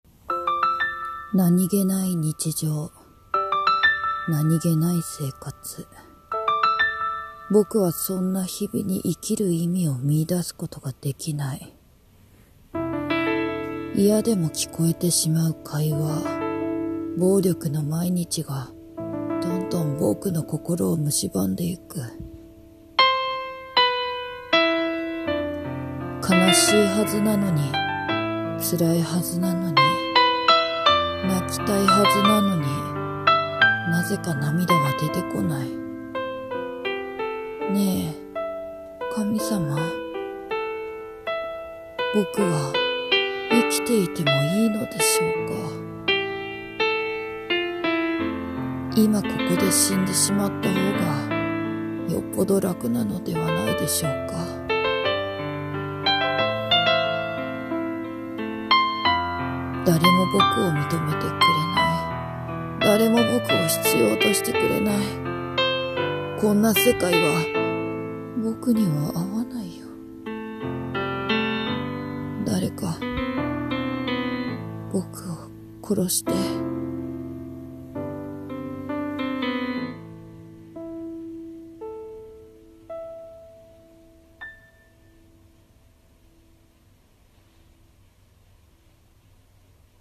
認情【一人声劇台本